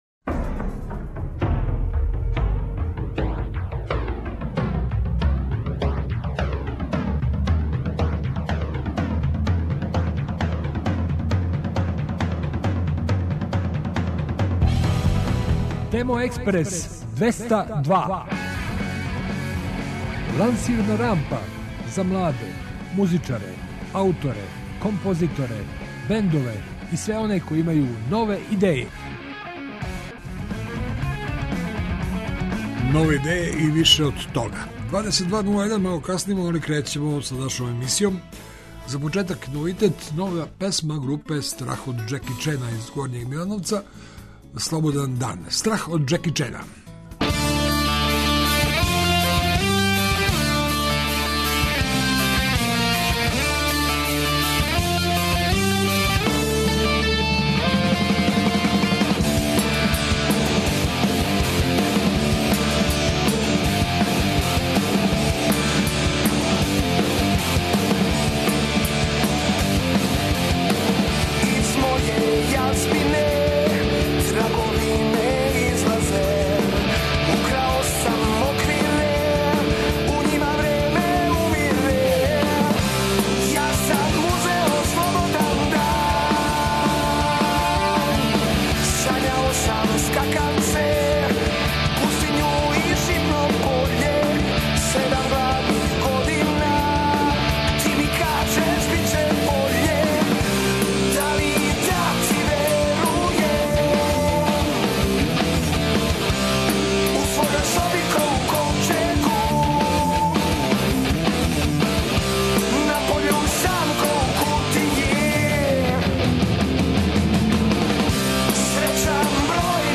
И у овонедељном издању емисије слушамо нове снимке и најављујемо концерте.